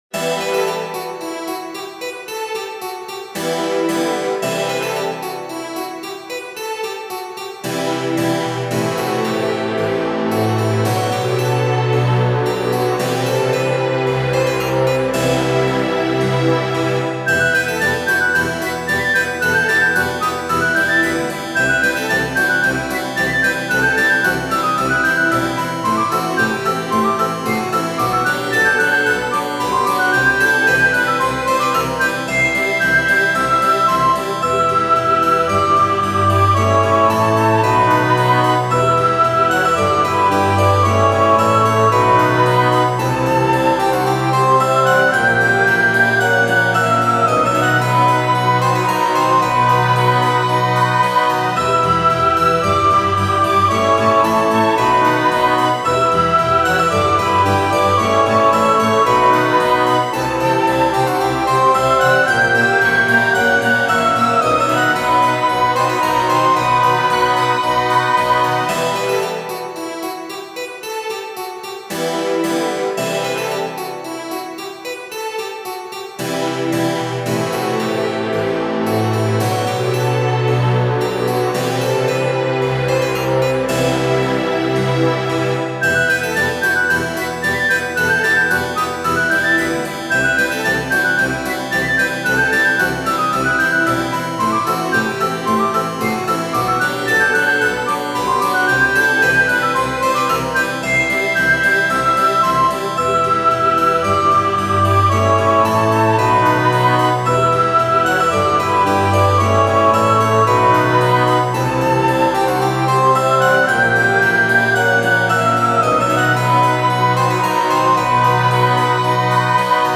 クラシカル